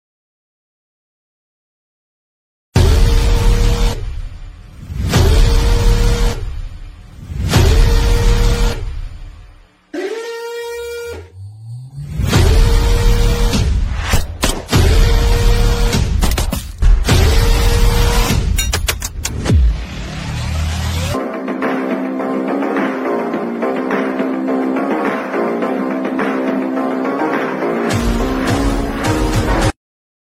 Categories BGM Ringtones